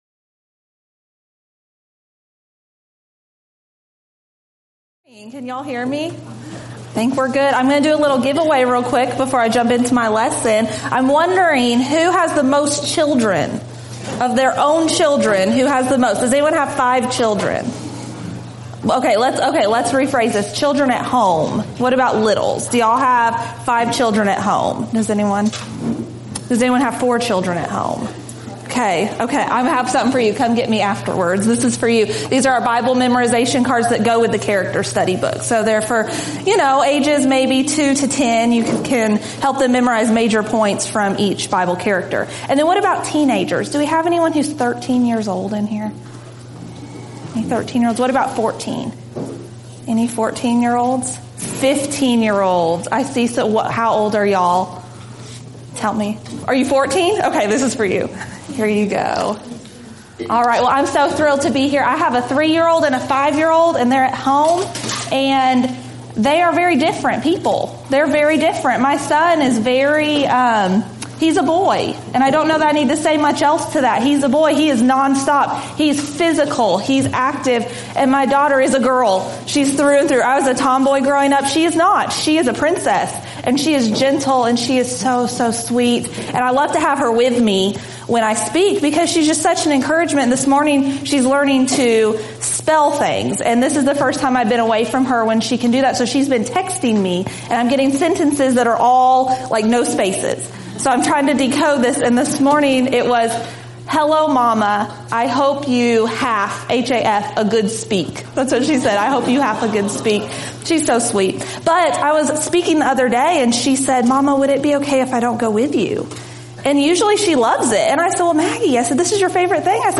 Alternate File Link File Details: Series: Arise: Southwest Spiritual Growth Workshop Event: 7th Annual Arise: Southwest Spiritual Growth Workshop Theme/Title: Arise with Conviction!
Ladies Sessions